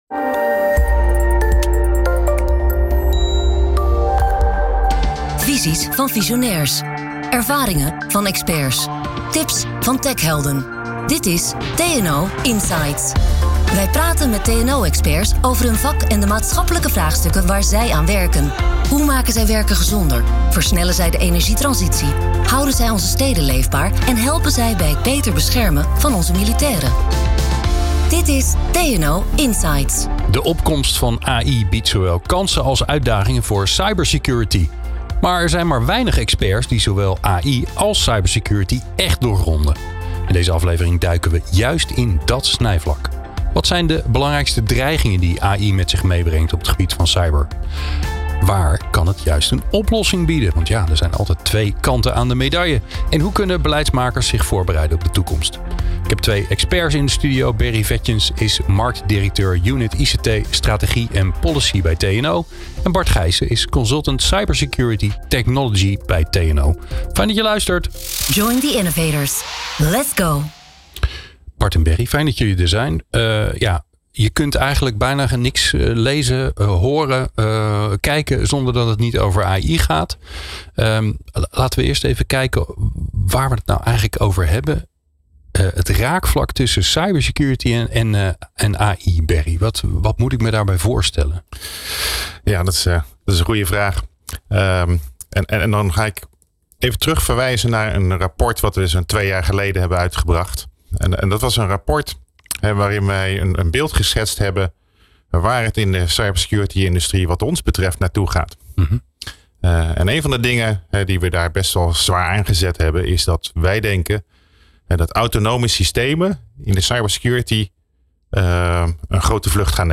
gaat in gesprek met twee experts: